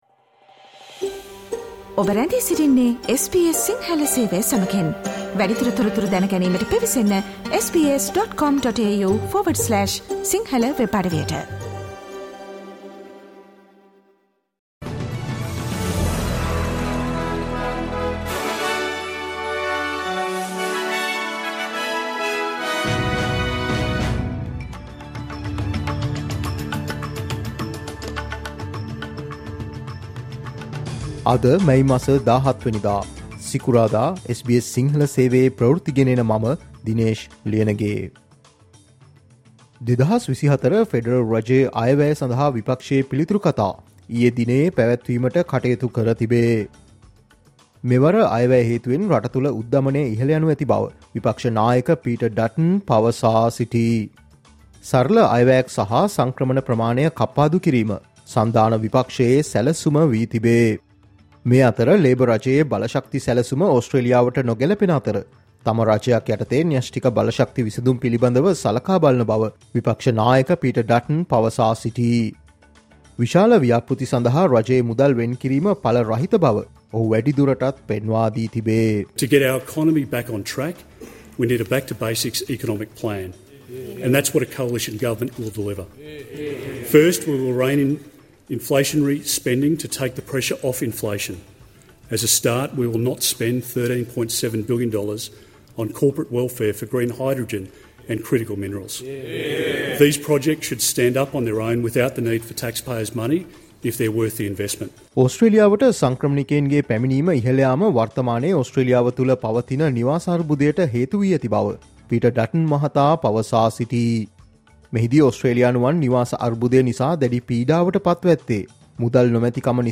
Australia news in Sinhala, foreign and sports news in brief - listen, today – Friday 17 May 2024 SBS Radio News